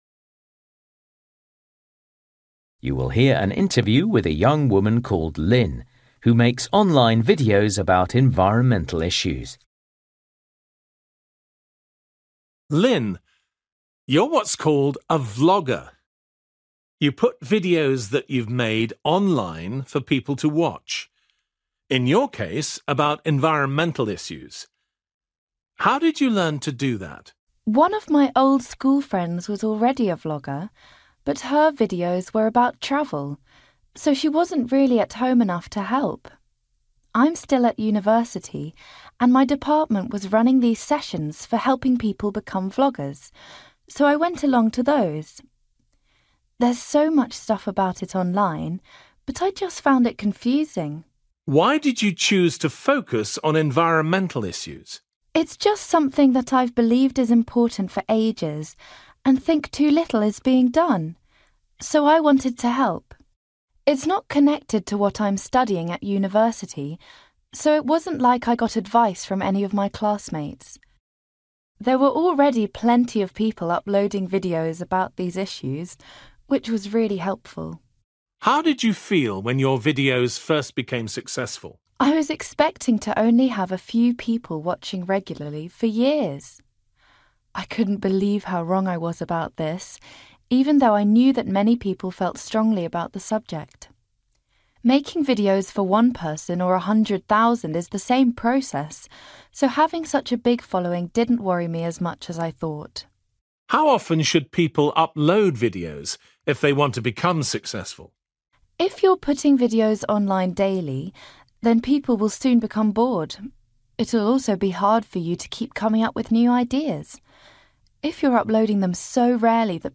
Bài tập trắc nghiệm luyện nghe tiếng Anh trình độ trung cấp – Nghe một cuộc trò chuyện dài phần 6